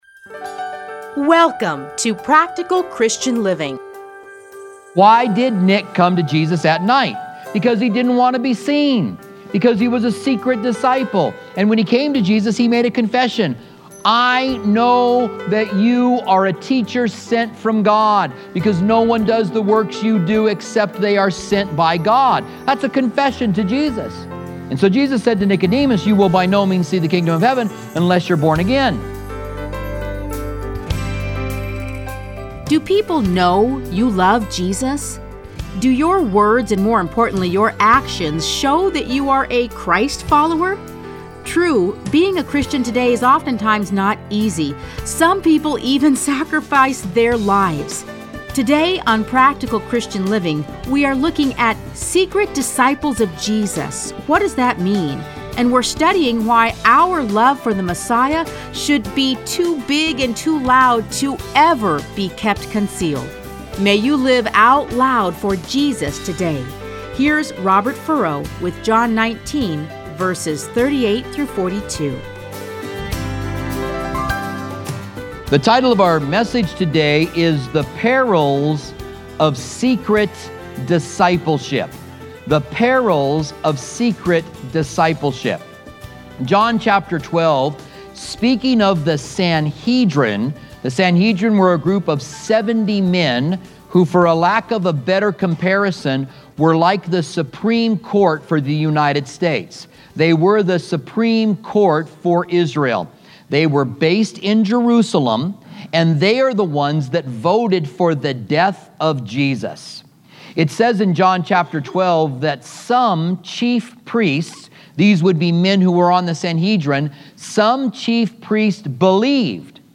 Listen to a teaching from John 19:38-42.